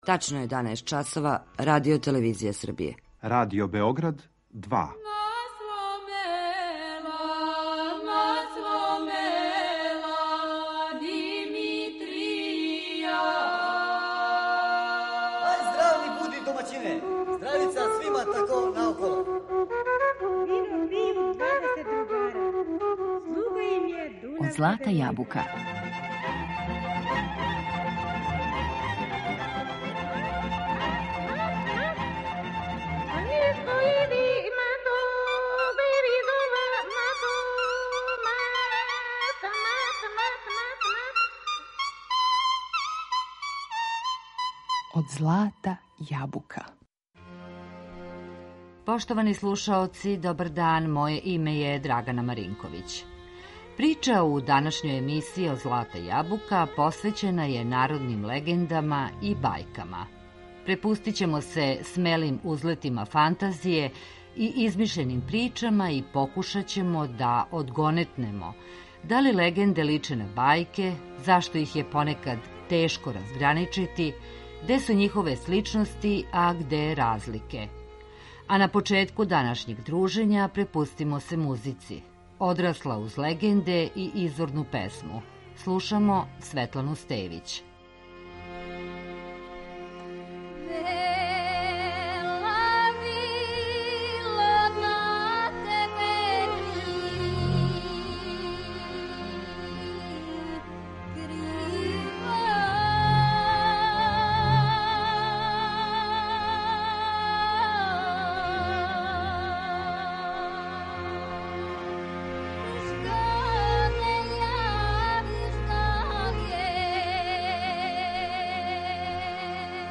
И традиционалну народну музику, која прати нашу причу, вековима је стварала машта народа.